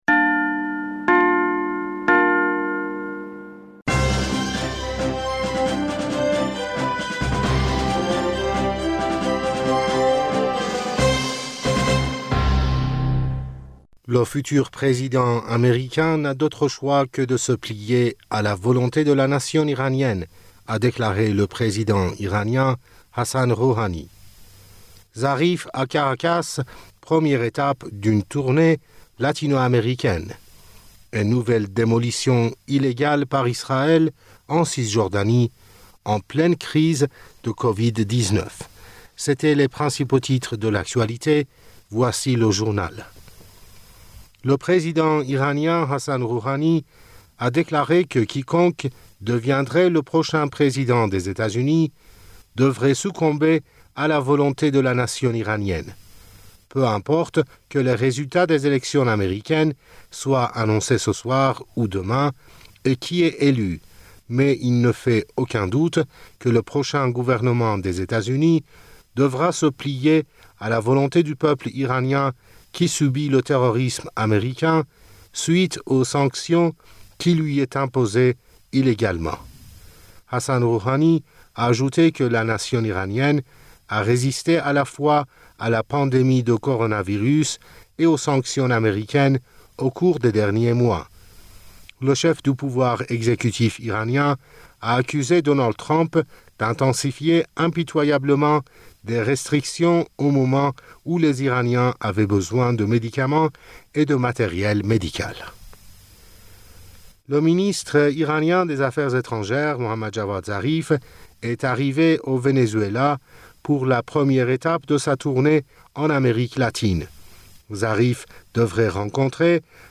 Bulletin d'informationd u 05 November 2020